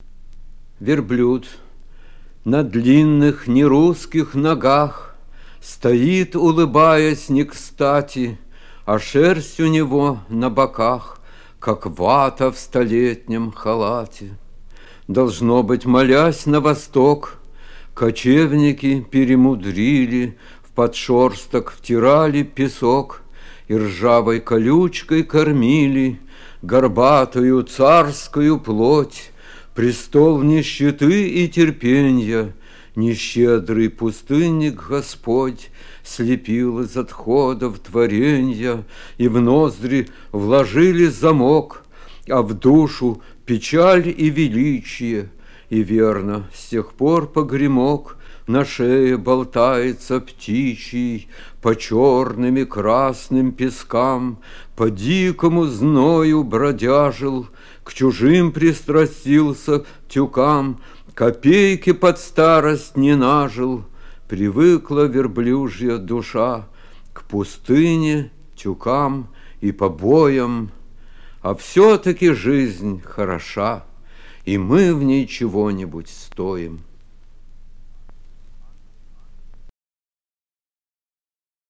2. «Арсений Тарковский читает свои стихи – Верблюд» /
arsenij-tarkovskij-chitaet-svoi-stihi-verblyud